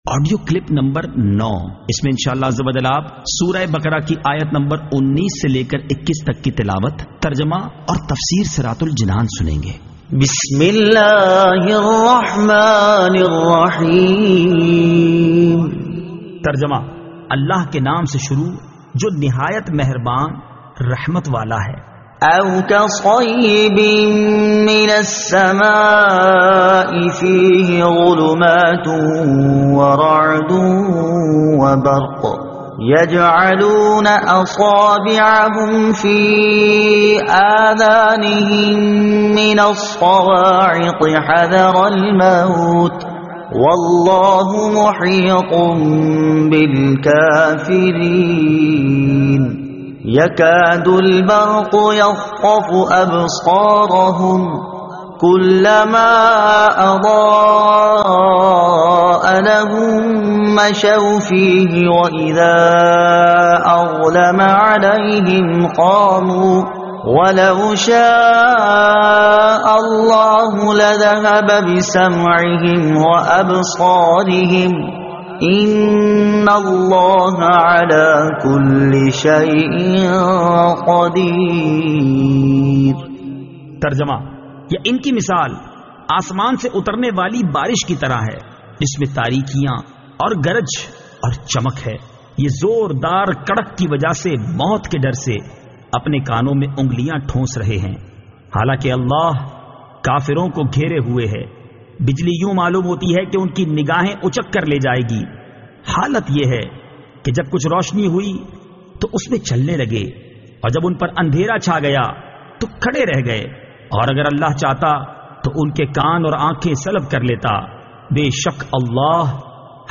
Surah Al-Baqara Ayat 19 To 21 Tilawat , Tarjuma , Tafseer